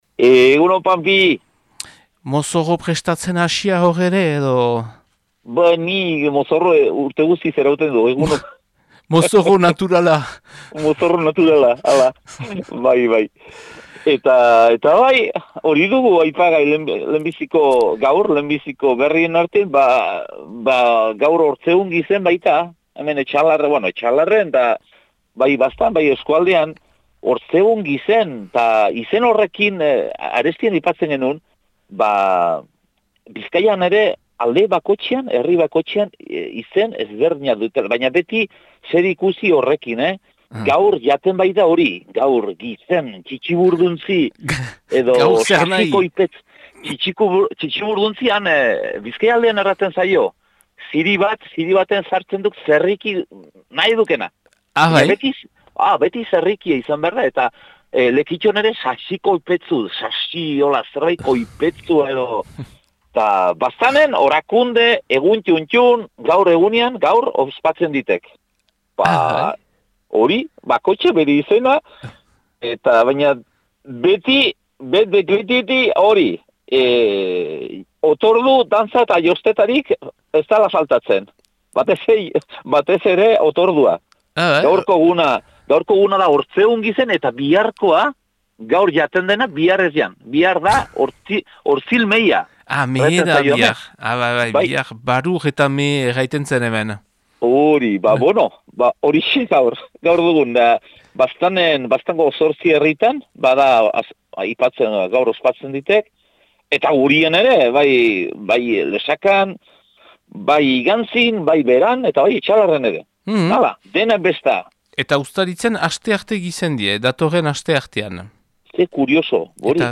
Baztan eta inguruko berriak